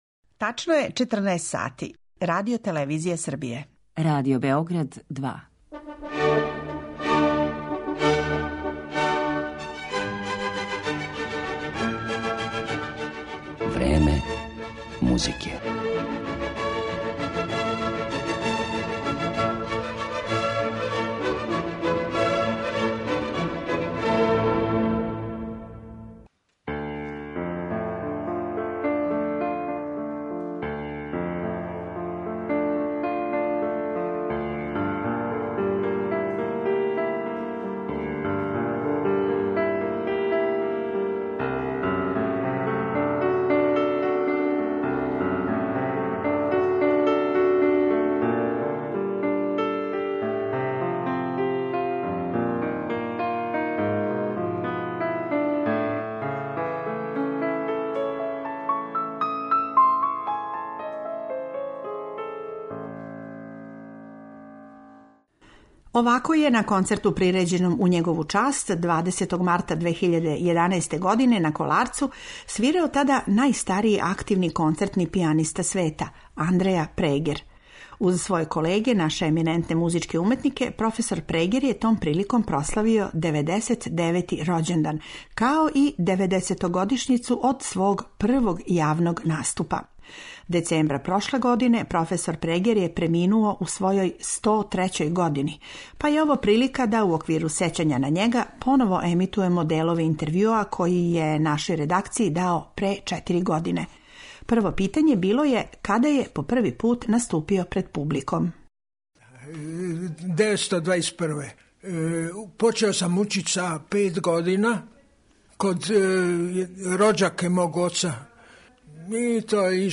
Емитоваћемо разговор са овим доајеном наше музике, у којем је, осим о свом необичном и бурном животу, говорио и o својим естетским и етичким начелима, пијанизму и музичкој педагогији.